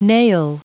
Prononciation du mot nail en anglais (fichier audio)
Prononciation du mot : nail